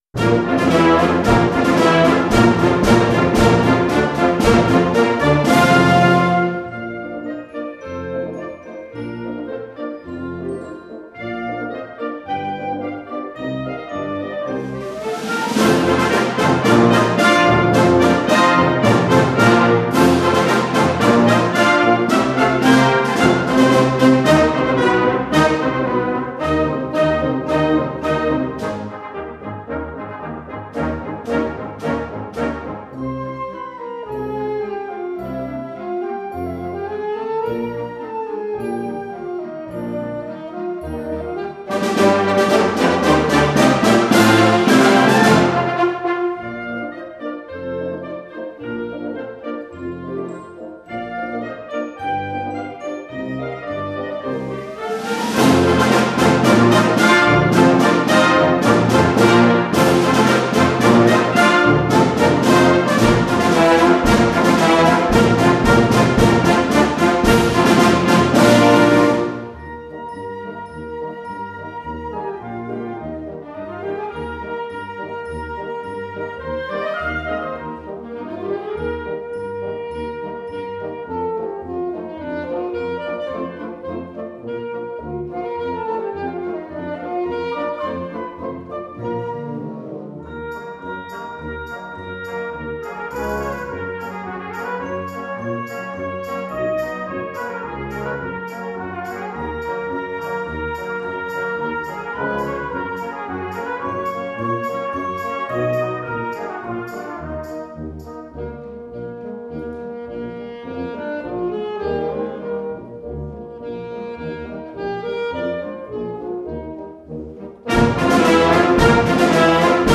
Genre: Band
concert march
score is in Bb
Percussion 1* (glockenspiel, xylophone)